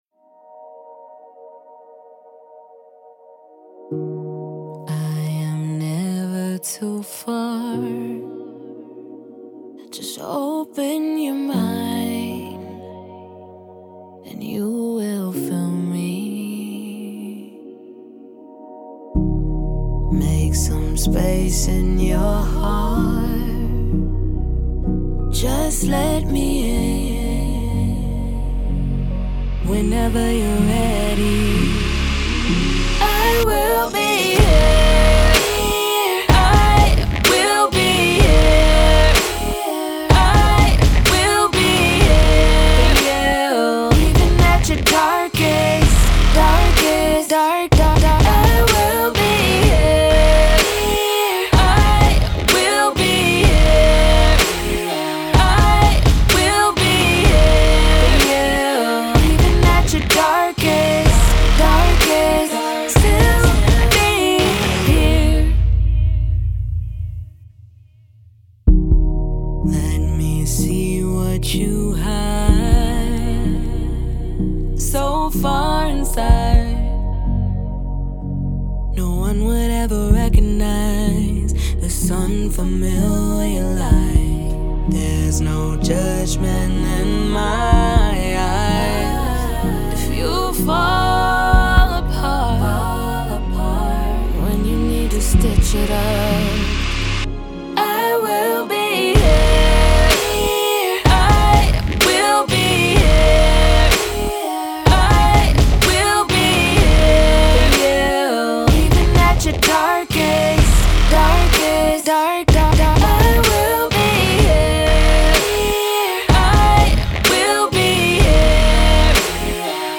Pop
Eb Min